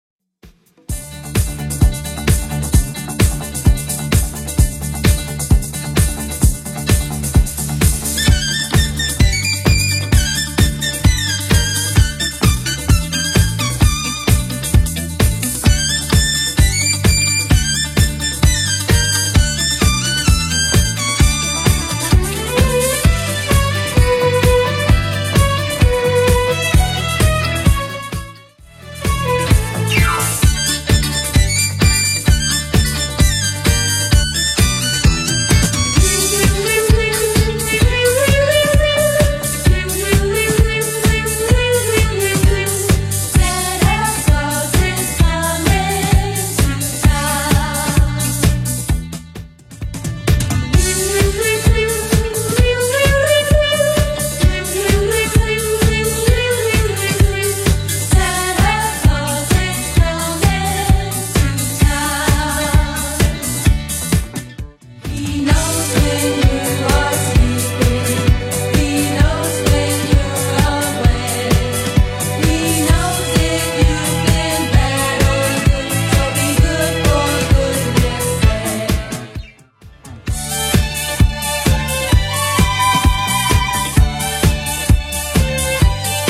Genre: 70's Version: Clean BPM: 115